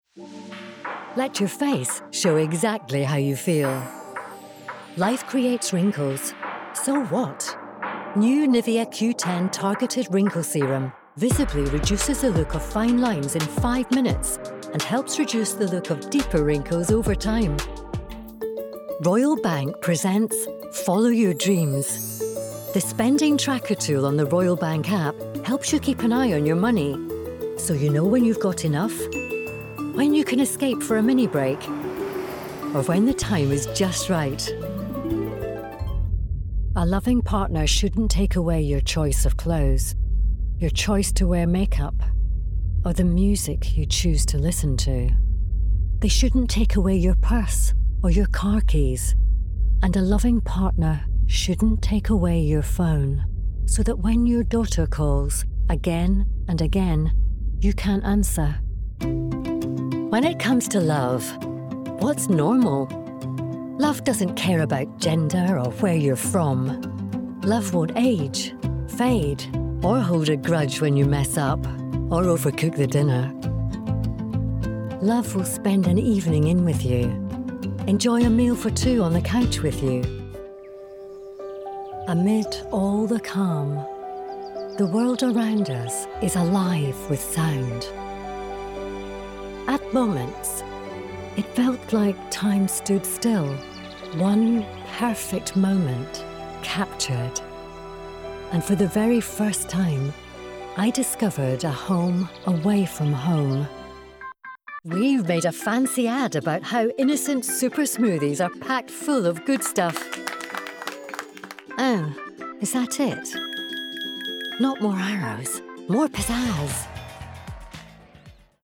Engels (Schotland)
Volwassen, Vriendelijk, Warm
Commercieel